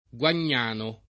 Guagnano [ gU an’n’ # no ]